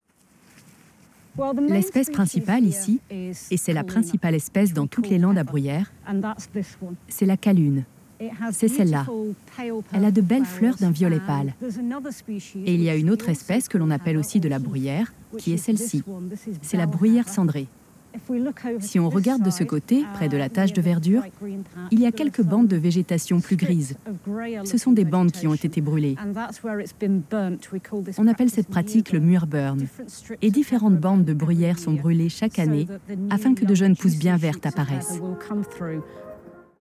Voice Over Arte voix douce voix impliquée voix posée Voix impliquée Catégories / Types de Voix Extrait : Votre navigateur ne gère pas l'élément video .